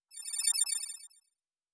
pgs/Assets/Audio/Sci-Fi Sounds/Interface/Data 10.wav at master